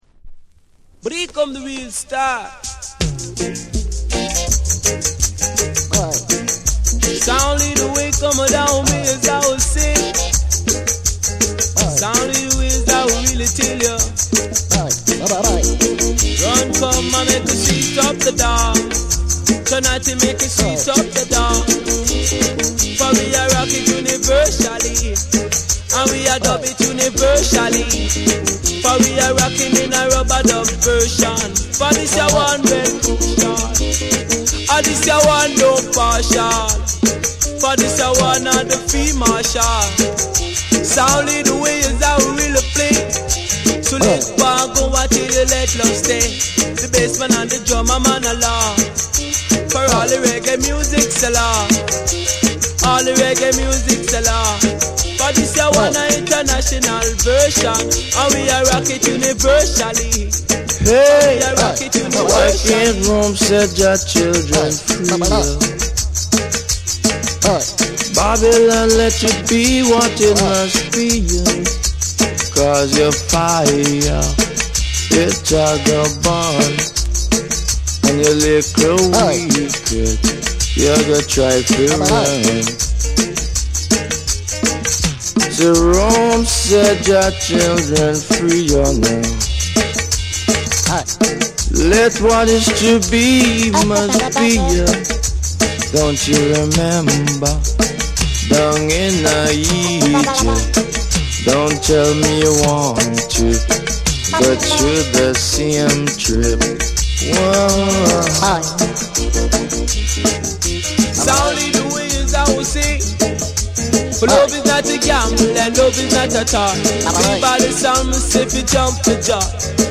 ミニマルで奥行きあるプロダクションと、ルーツ〜ダンスホール前夜の空気をまとった独特の世界観は今聴いてもフレッシュ！